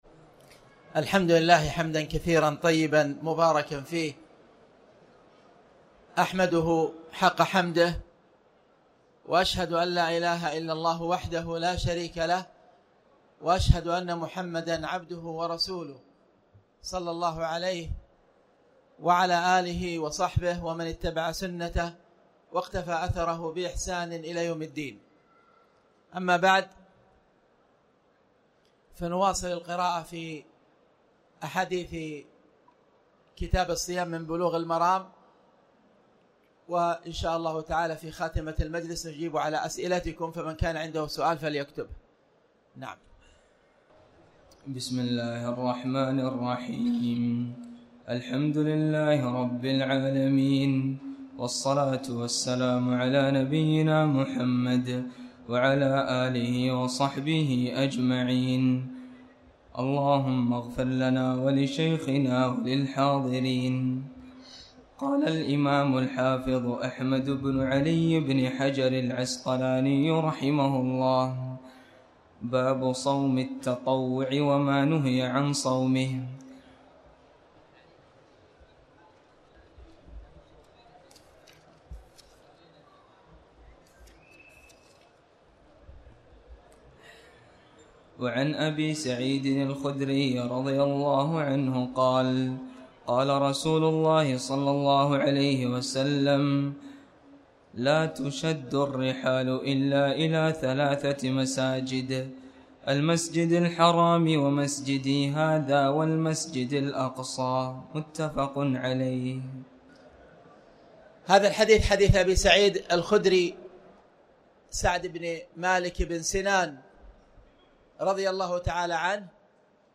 تاريخ النشر ٢١ رمضان ١٤٣٩ هـ المكان: المسجد الحرام الشيخ